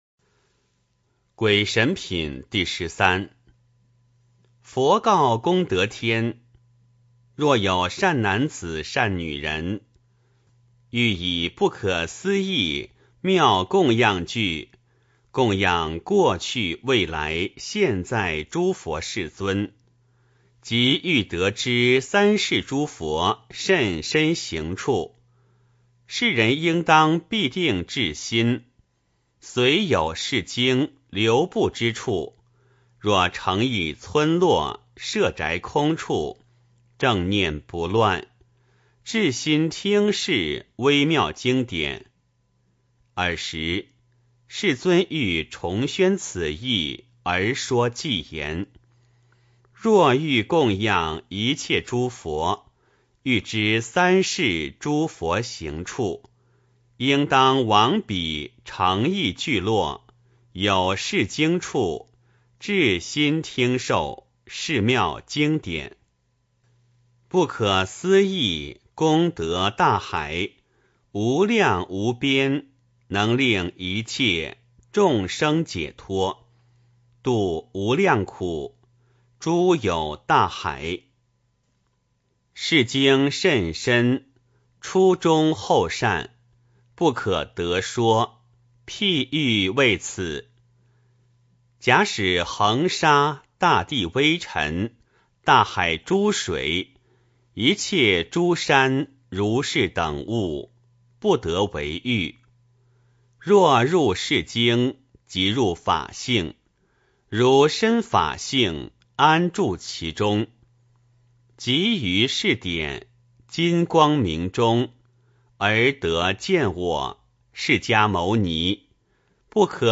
金光明经-鬼神品第十三 诵经 金光明经-鬼神品第十三--未知 点我： 标签: 佛音 诵经 佛教音乐 返回列表 上一篇： 金光明经-善集品第十二 下一篇： 金光明经-赞佛品第十八 相关文章 七佛如来名号+释迦如来宝髻如来名号 七佛如来名号+释迦如来宝髻如来名号--海涛法师领众...